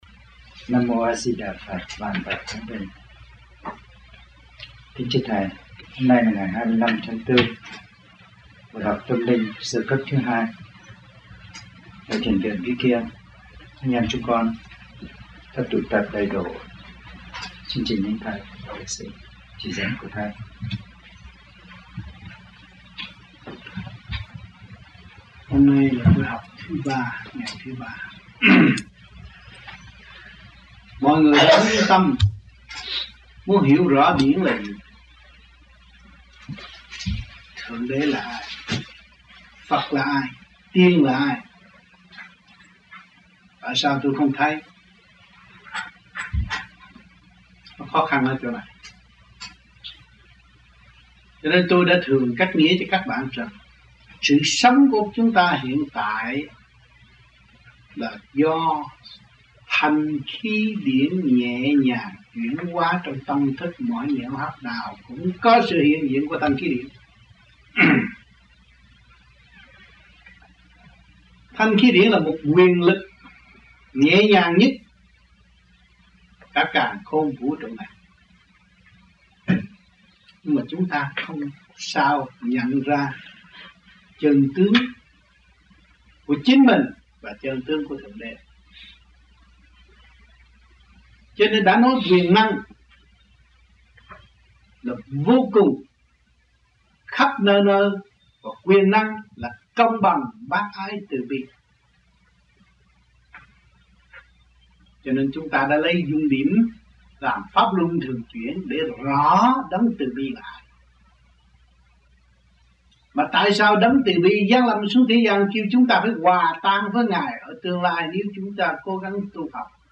1986 Thiền Viện Vĩ Kiên - Khóa 2